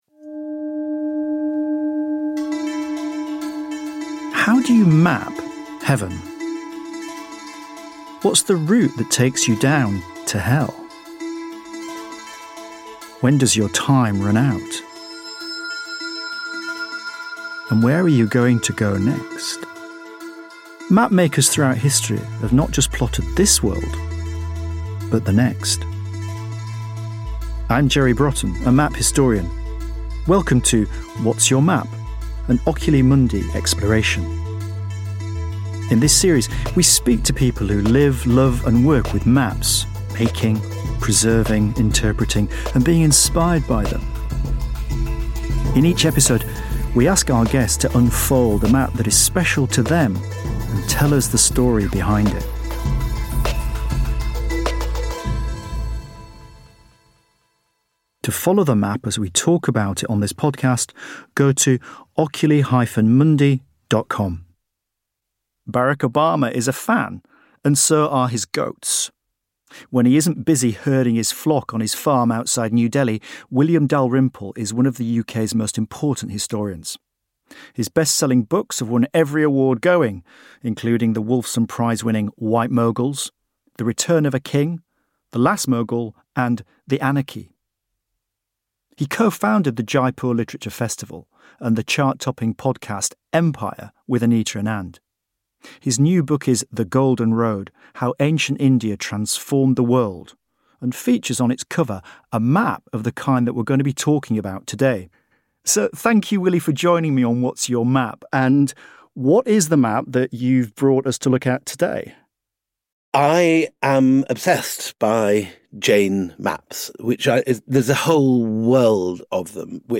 Welcome to the first episode of What’s Your Map? where host Jerry Brotton is joined by historian William Dalrymple, who unfurls a beautiful Jain cosmological map to explore the meaning and history of the ancient Indian religion.